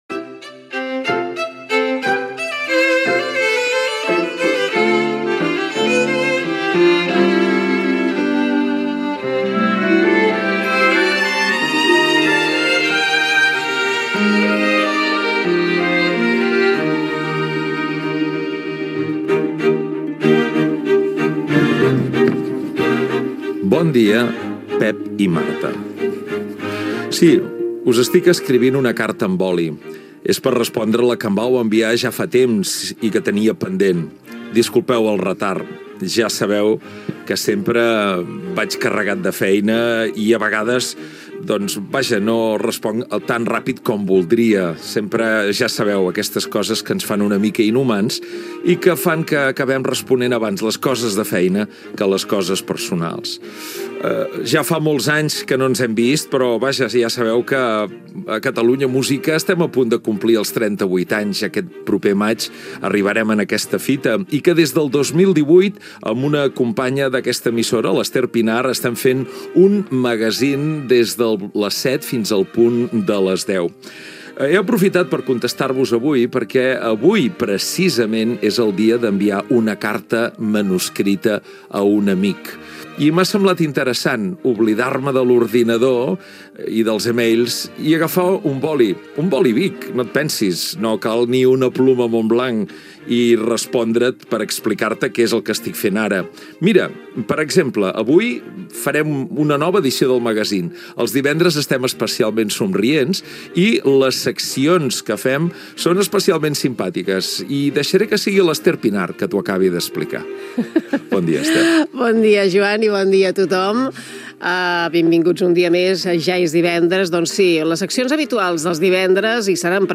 Sintonia, carta manuscrita, sumari de continguts, data, indicatiu del programa, equip, 300 anys de la mort del compositor Johann Philipp Krieger
Musical